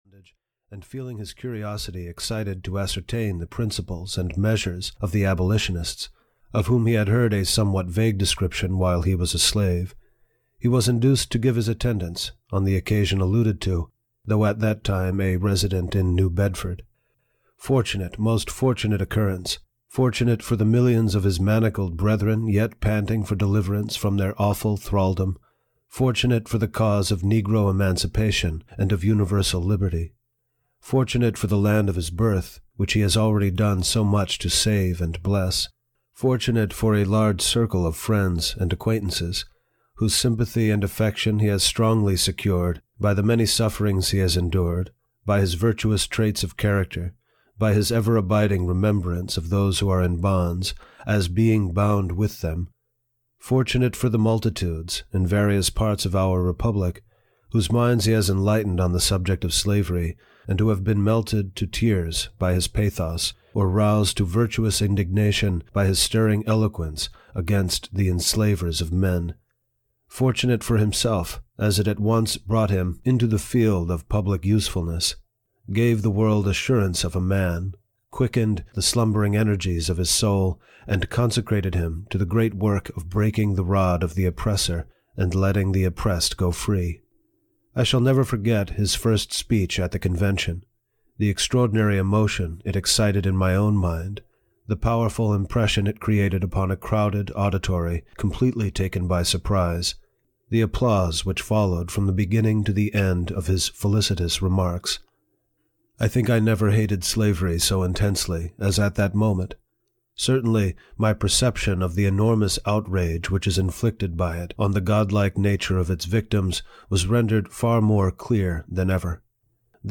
Audio knihaNarrative of the Life of Frederick Douglass (EN)
Ukázka z knihy